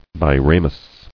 [bi·ra·mous]